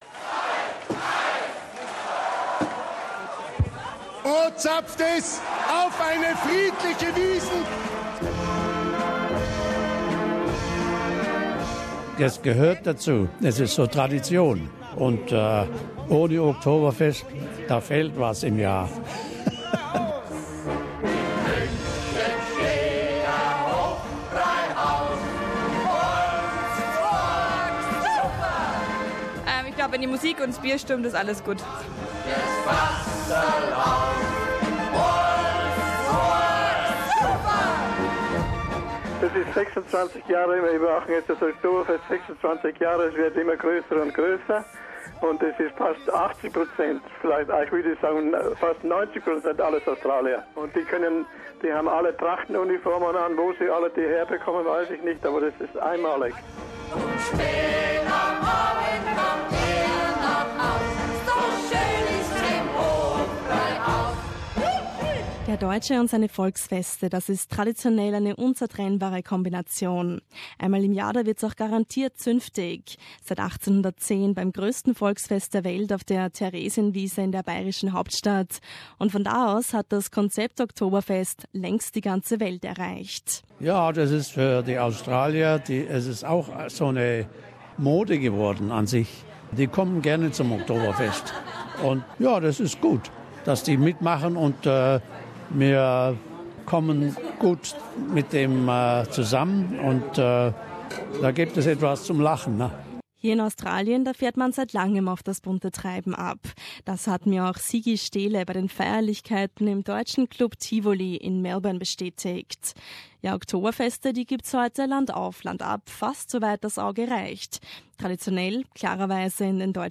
Wir haben uns für diese Audio-Reportage umgehört bei Veranstaltern und Besuchern in Victoria, New South Wales und in Queensland wie und von wem hierzulande gefeiert wird. Und festgestellt, dass Oktoberfeste keine rein deutsche Angelegenheit mehr sind.